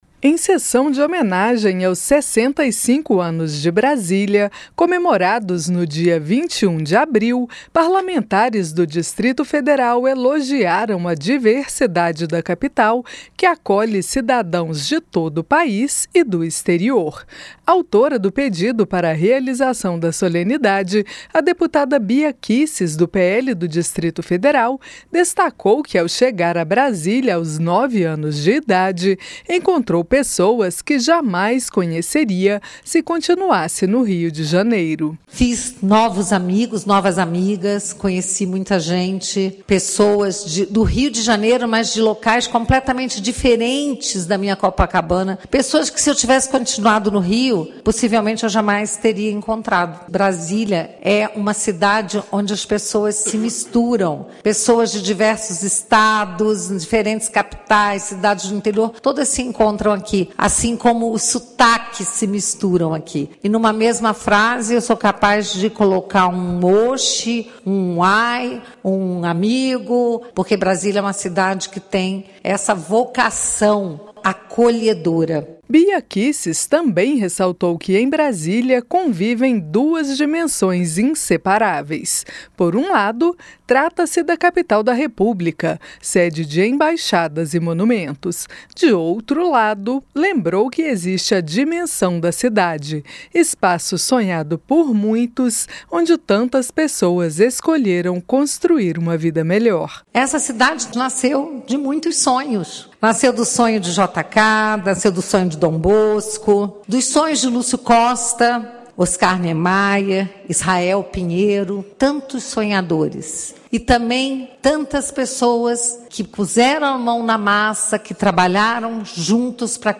Câmara realizou sessão solene nesta quinta-feira